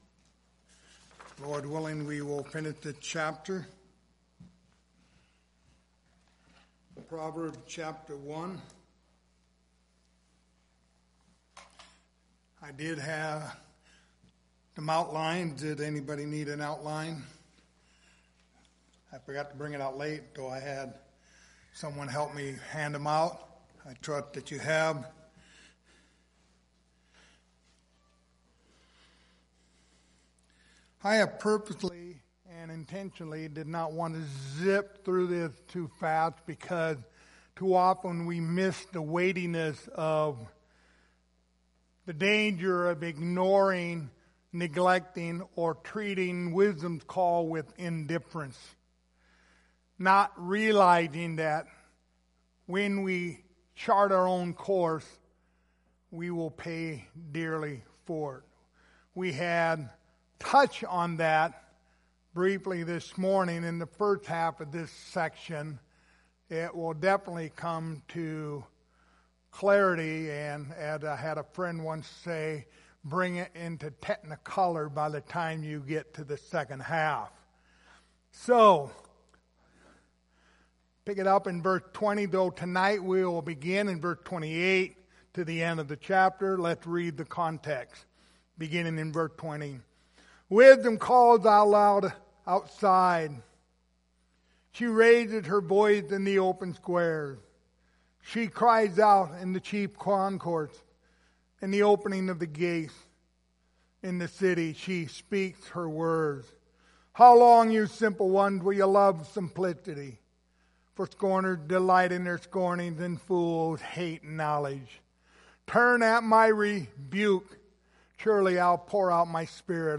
The Book of Proverbs Passage: Proverbs 1:28-33 Service Type: Sunday Evening Topics